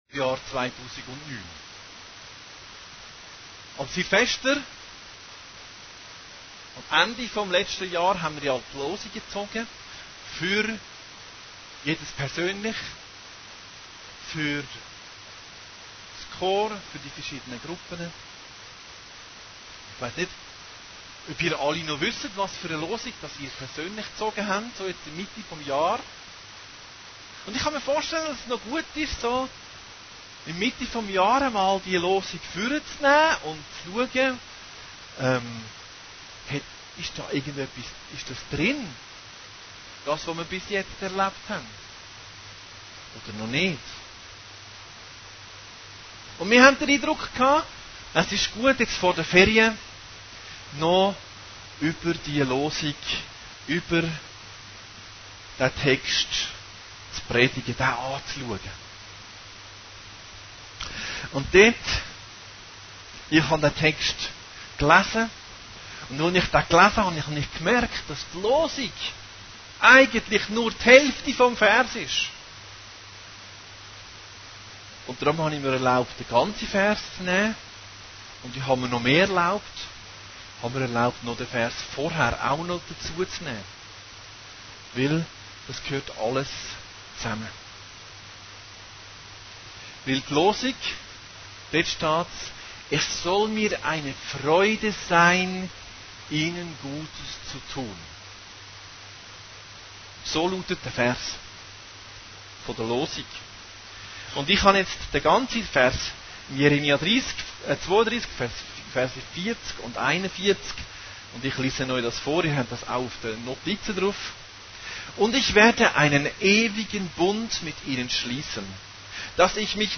Allgemeine Predigten Date